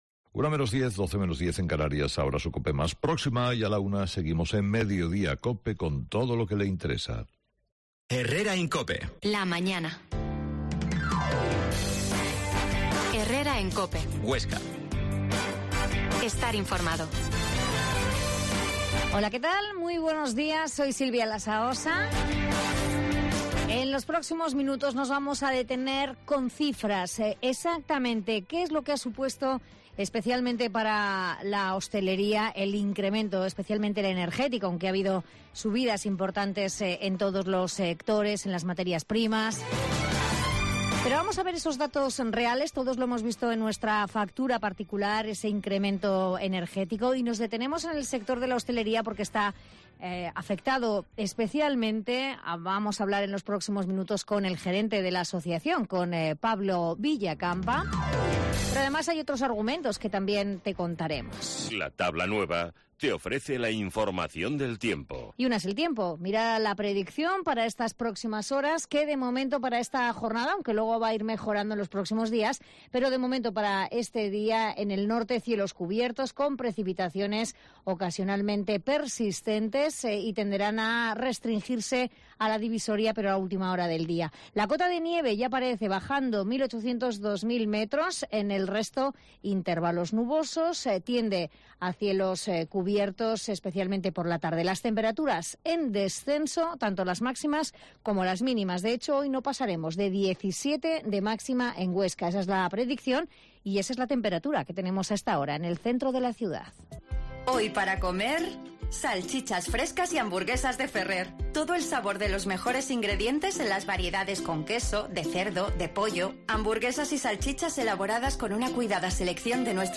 La Mañana en COPE Huesca - Magazine Herrera en COPE Huesca12.50h Entrevista al gerente de la Asoc.